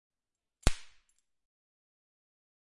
SFX图书馆 " 皮带鞭子 - 声音 - 淘声网 - 免费音效素材资源|视频游戏配乐下载
用变焦和变焦MSH6 MS胶囊录制，腰带的音频文件被折叠并拍打在一起。记录在一个小房间里。